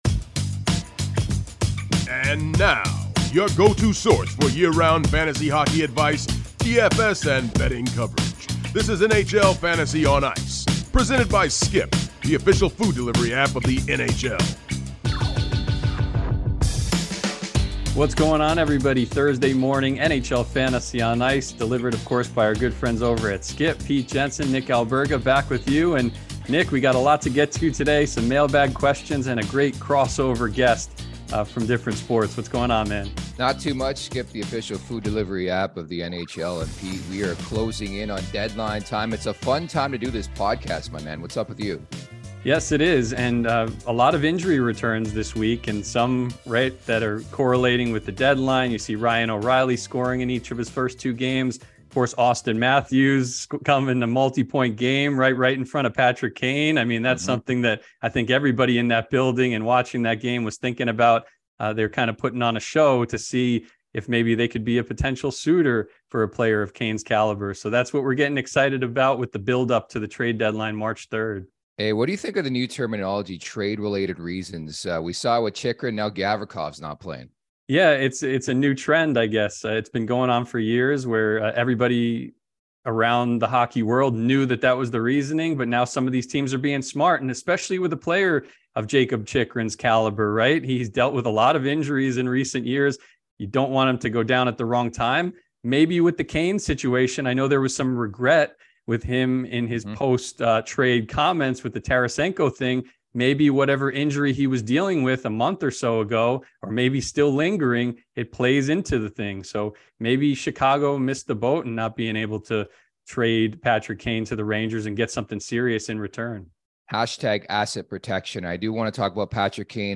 NHL Fantasy on Ice